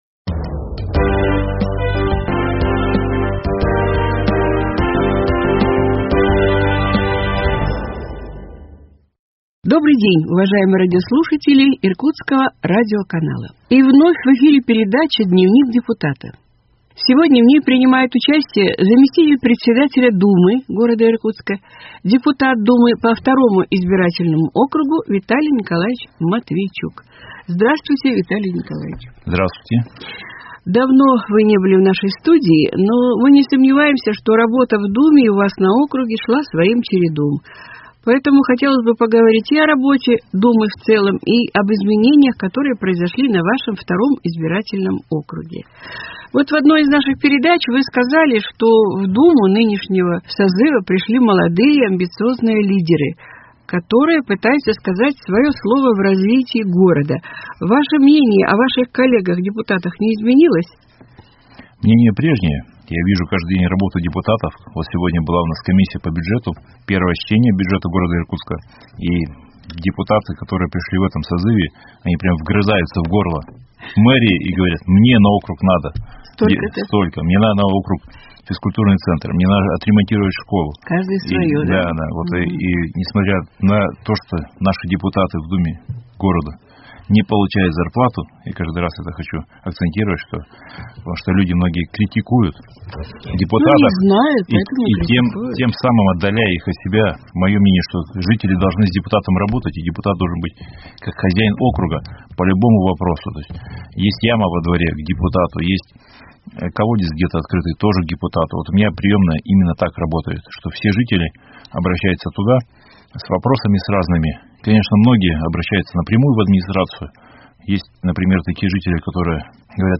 Депутат Думы г. Иркутска по избирательному округу № 2 Виталий Николаевич Матвийчук отвечает на вопросы иркутян, рассказывает об итогах работы, основных задачах, путях решения проблем, планах на будущее.